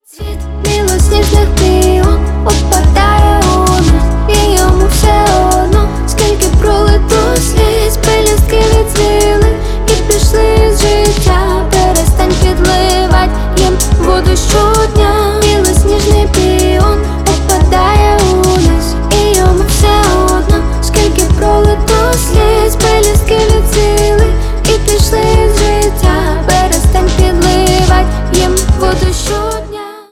грустные , инди , поп